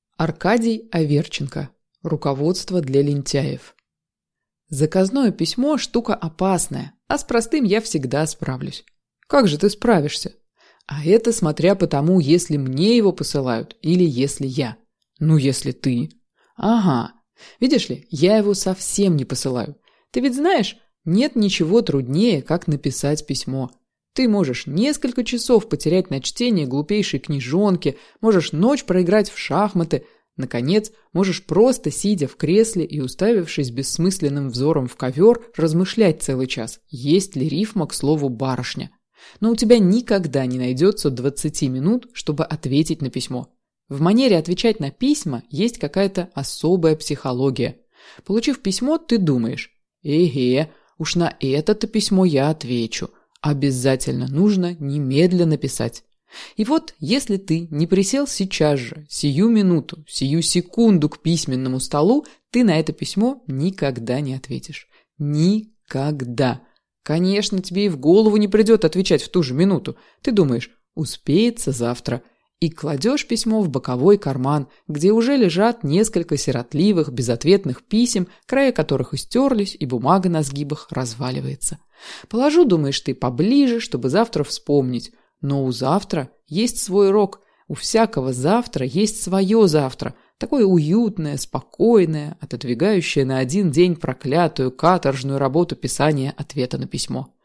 Аудиокнига Руководство для лентяев | Библиотека аудиокниг